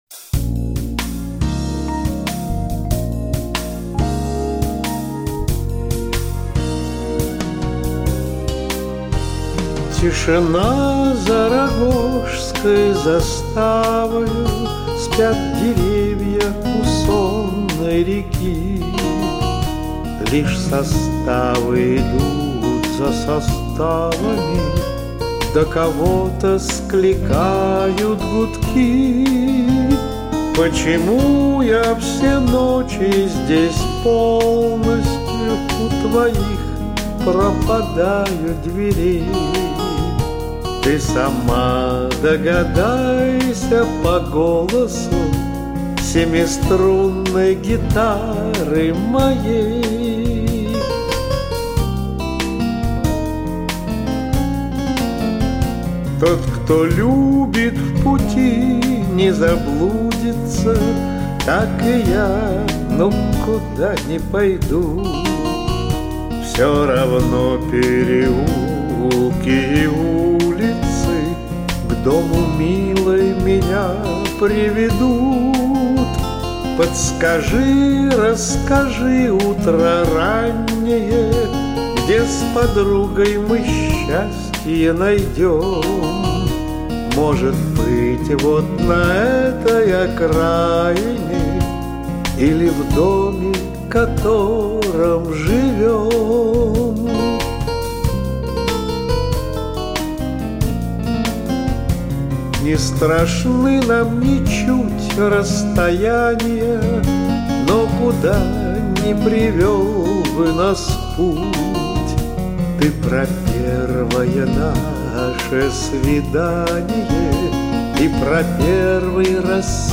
Режим: Stereo